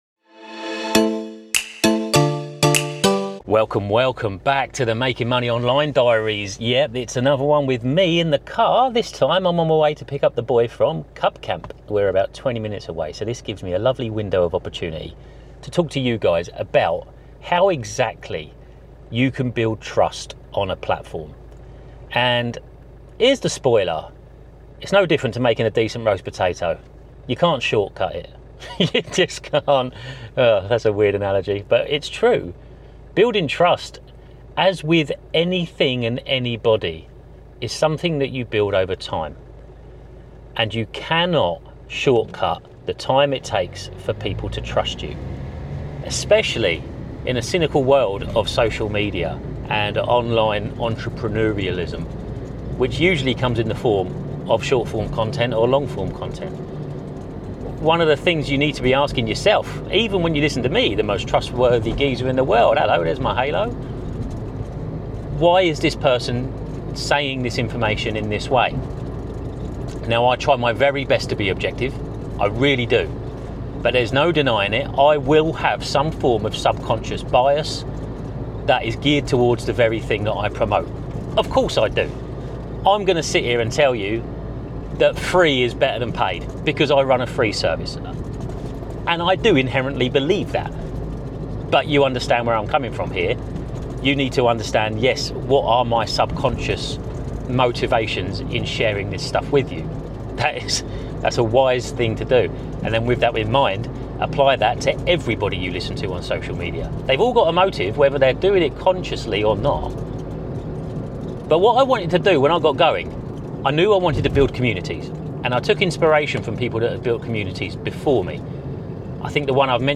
In this solo car-chat episode, I dive into one of the most underrated yet powerful tools in online business: trust. I talk about how I built mine from scratch, why giving first is essential, and why it's so easy to lose everything if you don’t stay true to yourself.